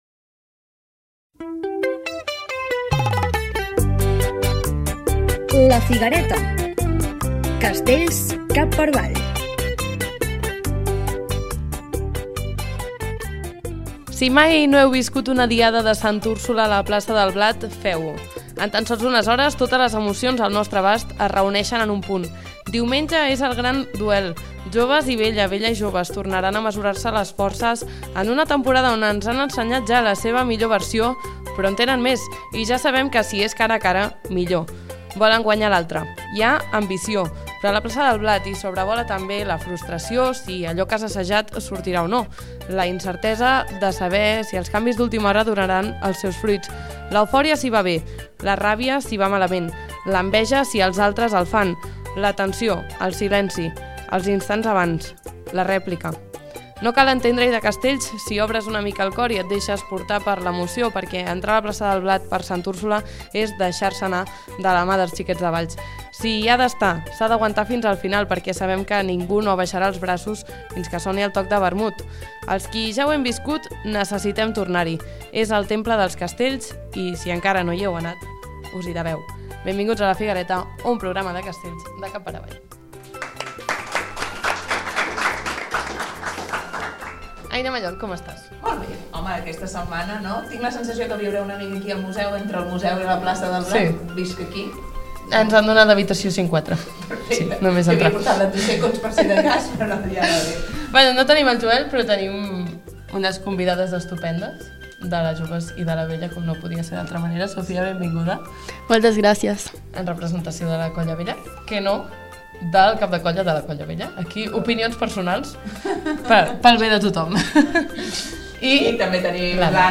A ‘La Figuereta’ fem un programa des del Museu Casteller amb la prèvia de Santa Úrsula amb convidades de la Vella i la Joves. També repassem Girona, El Roser i l’Esperidió. A més, descobrim els bulos que circulen per Valls, les opos castelleres per a Bombers i, finalment, el Joc del Calçofest per als forasters.